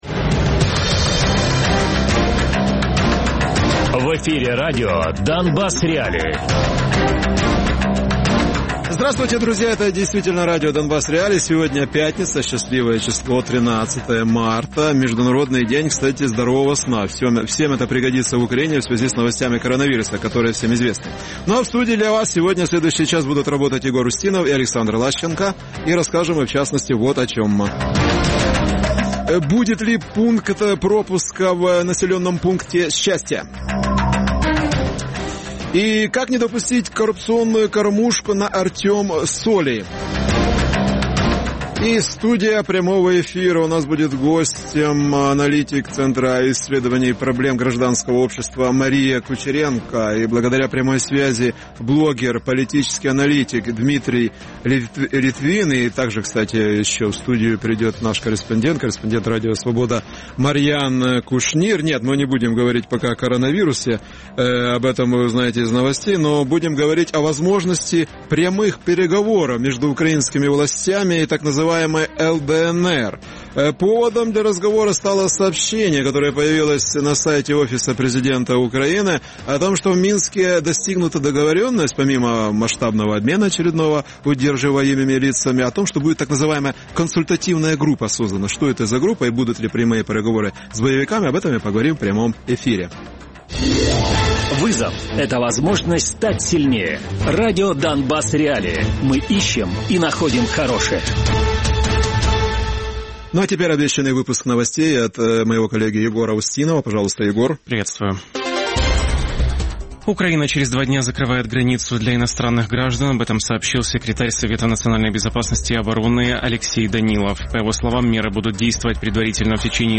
Гості в студії:
Радіопрограма «Донбас.Реалії» - у будні з 17:00 до 18:00.